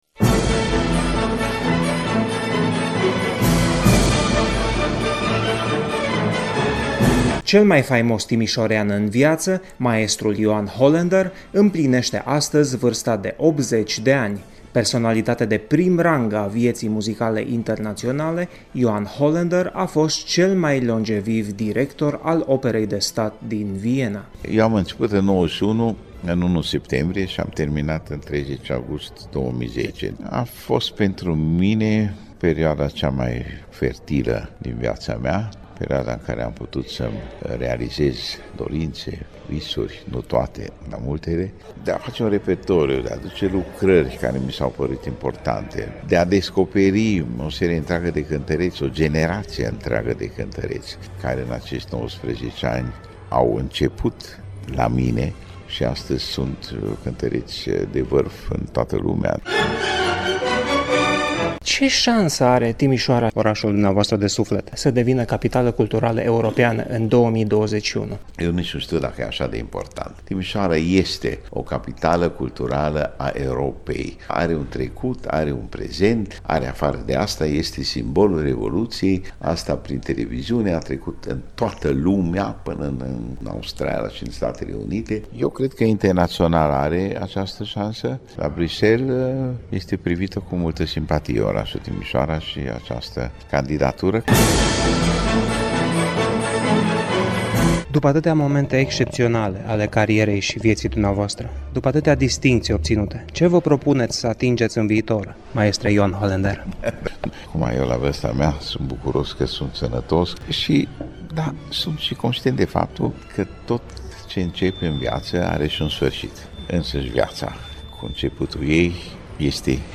INTERVIU / Cel mai longeviv director al Operei din Viena, Ioan Holender, împlinește 80 de ani - Radio România Timișoara